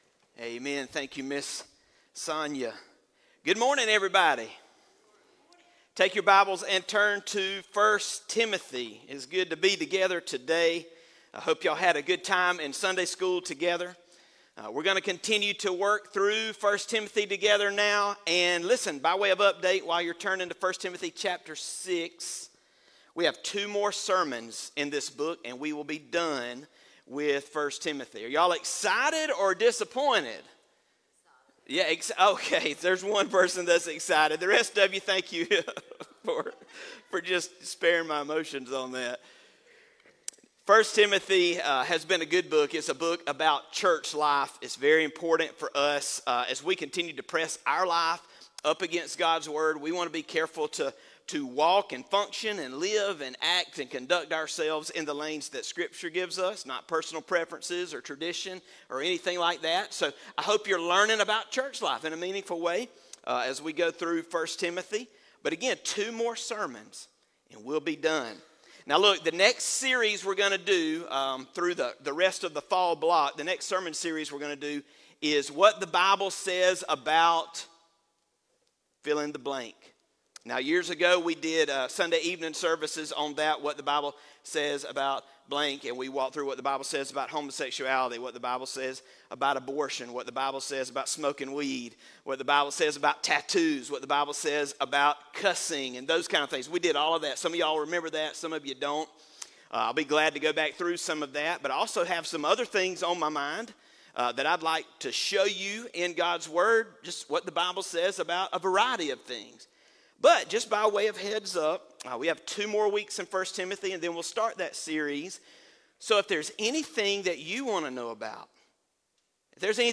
Sermon Audio 10-12.m4a